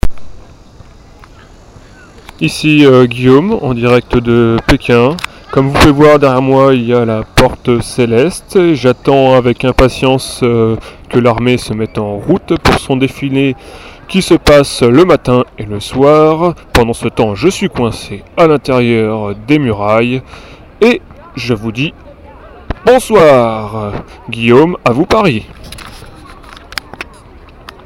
En direct de l'enceinte imperiale de la cite interdite, samedi 19 aout, Pekin, chine, posté le 2006-08-20 16:23:59